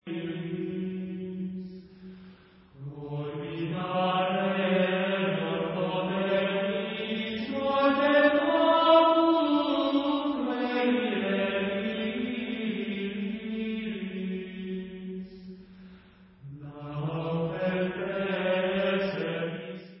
Leich